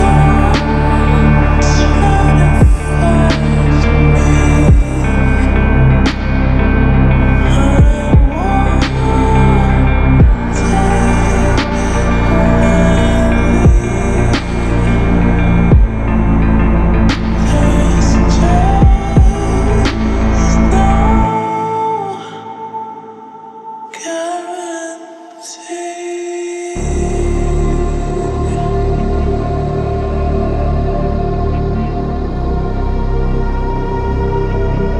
мелодию нарезки
Electronic
2023-03-10 Жанр: Электроника Длительность